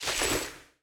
PixelPerfectionCE/assets/minecraft/sounds/item/armor/equip_chain5.ogg at mc116
equip_chain5.ogg